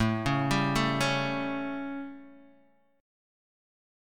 A7 Chord